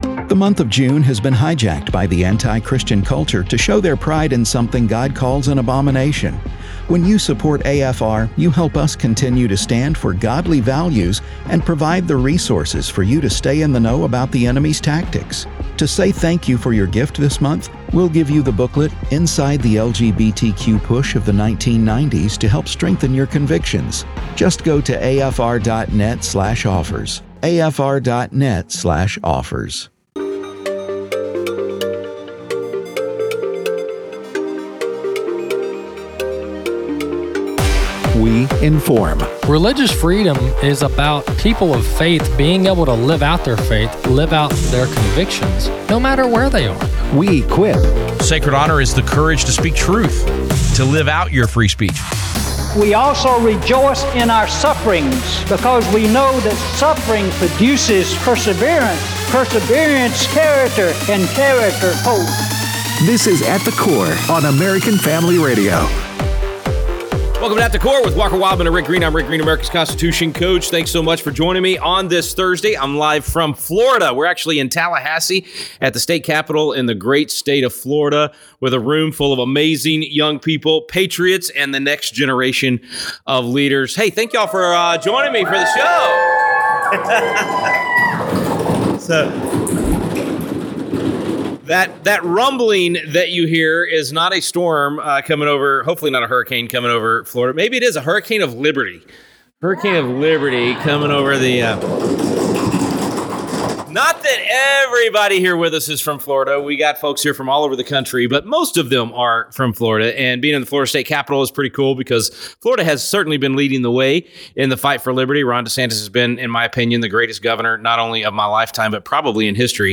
comes to us from the State Capitol of Florida in Tallahassee